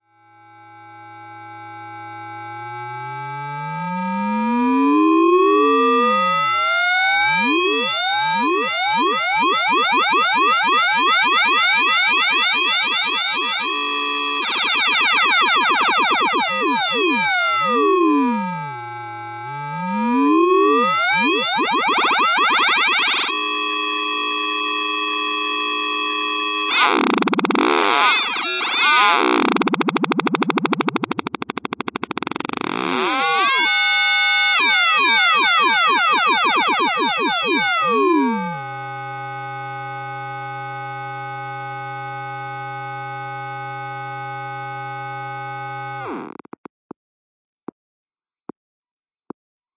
vcv扫荡故障
描述：一个振荡器调制另一个振荡器扫描频率的音量。采用VCV Rack模块化合成器制造
Tag: 电子 数字 毛刺 扫描 合成器 模块化 苛刻 噪声